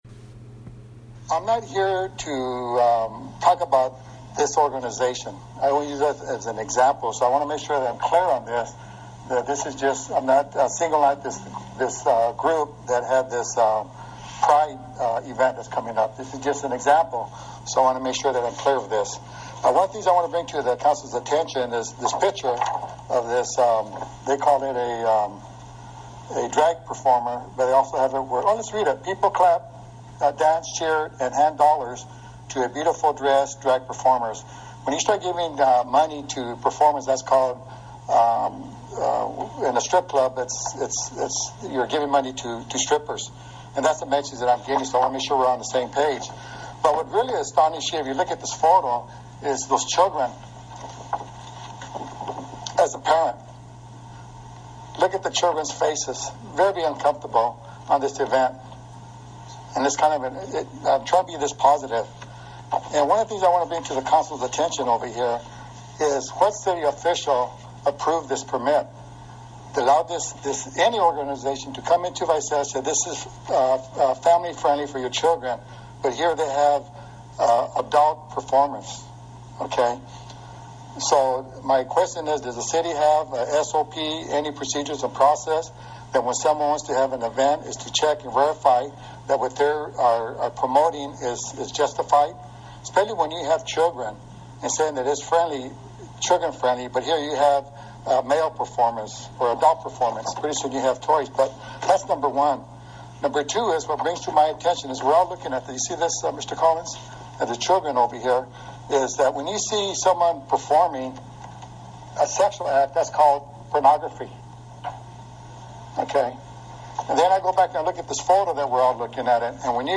Audio comments, City Council meeting May 21 2018 – Speaker lambastes city, LGBT group
Immediately following his remarks are mine, given a few minutes after his.
Sorry that the audio quality is not the best, either. It’s also a copy of a copy, especially that last few seconds.